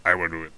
spearman_ack4.wav